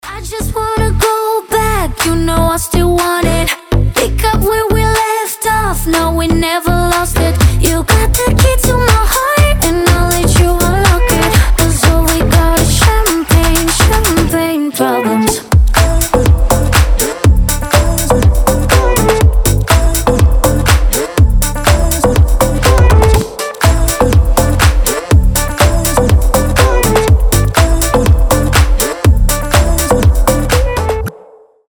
• Качество: 320, Stereo
заводные
женский голос
Dance Pop